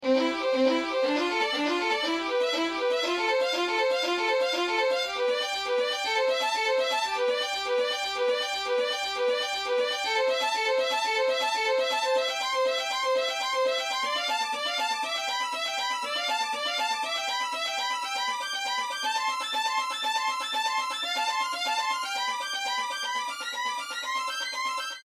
Also, the choice of instruments is based on a classic orchestra’s positioning, where I chose to place the market with the largest cap in the front; therefore, it is the violin.
thrill-euphoria-2-NA.mp3